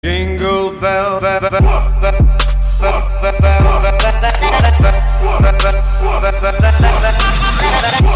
Download Christmas Jingle Bells sound effect for free.